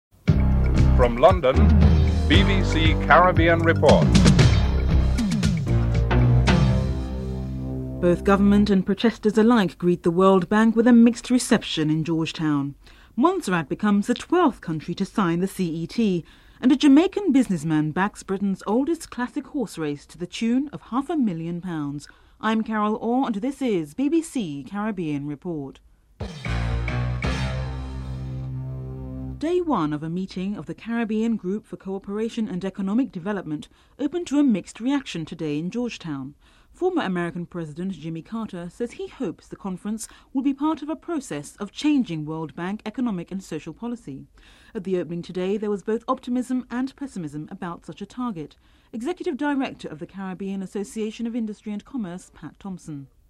Headlines (00:00-00:29)
Theme music (14:45-15:00)